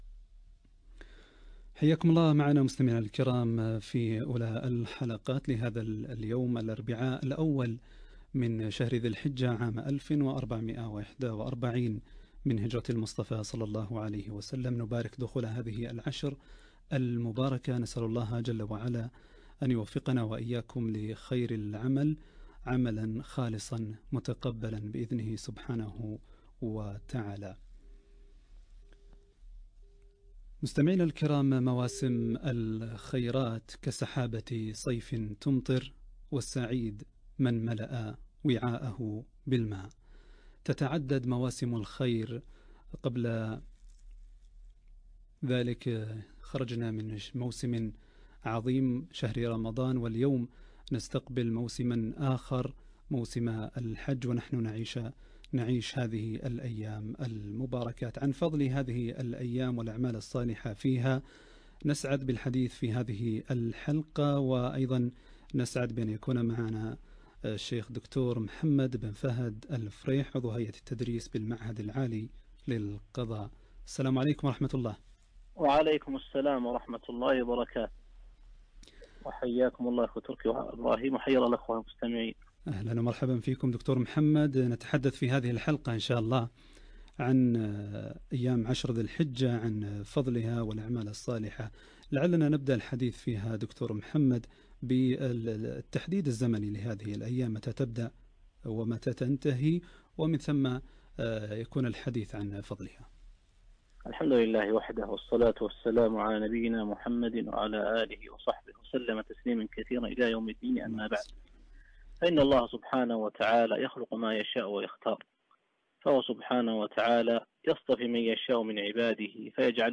أيام العشر والأعمال الصالحة فيه - لقاء إذاعي 1 ذي الحجة 1441 هـ